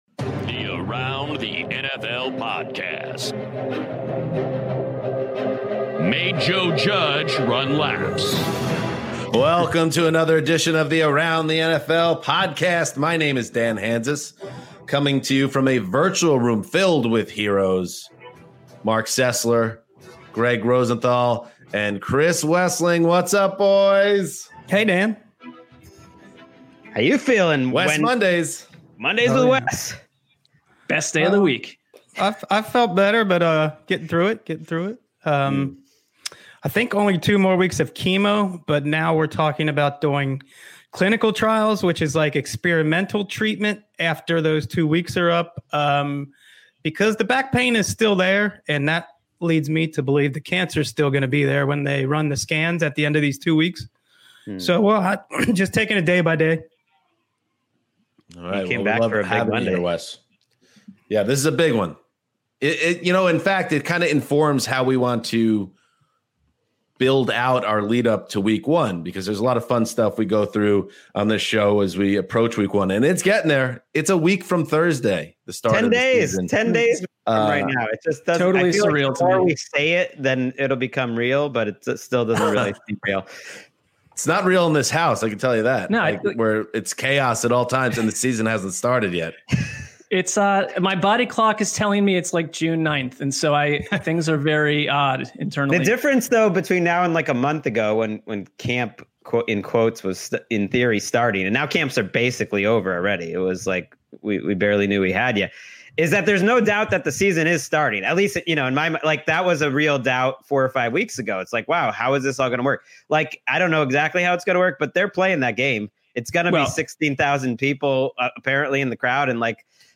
Plus, the guys discuss the Yannick Ngakoue trade.